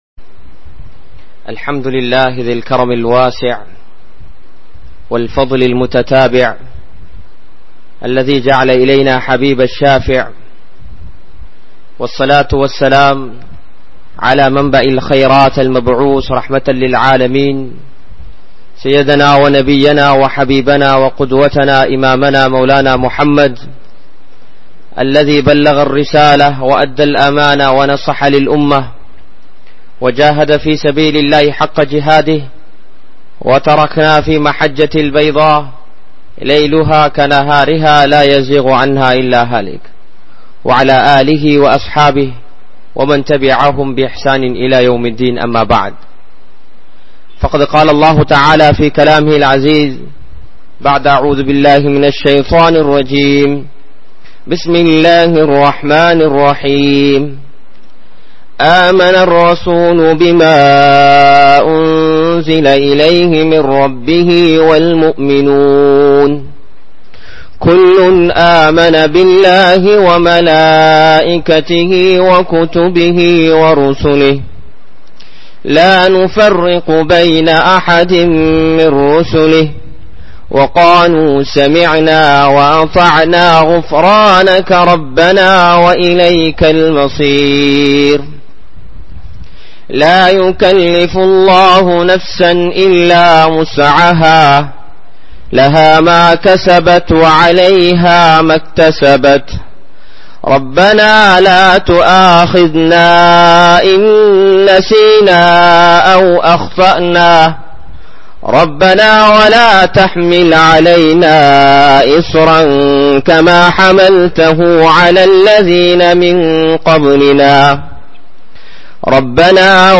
Mumeengalin Vaalkai (முஃமீன்களின் வாழ்க்கை) | Audio Bayans | All Ceylon Muslim Youth Community | Addalaichenai